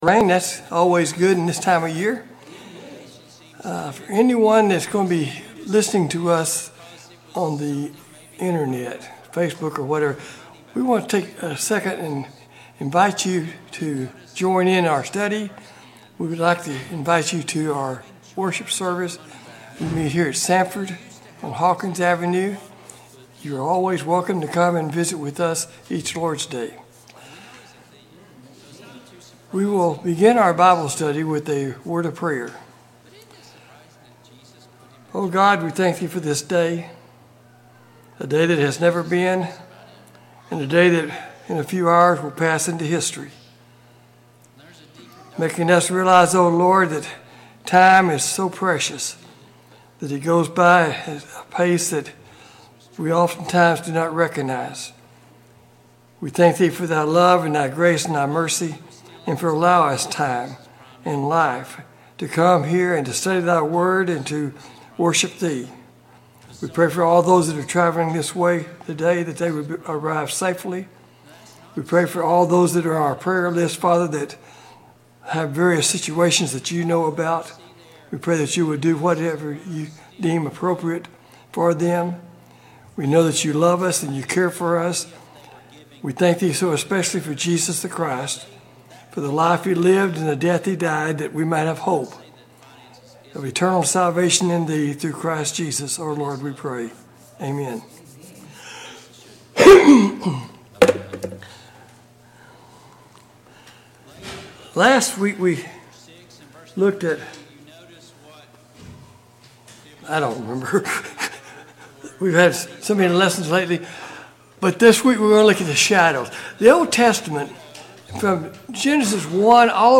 God's Scheme of Redemption Service Type: Sunday Morning Bible Class « Study of Paul’s Minor Epistles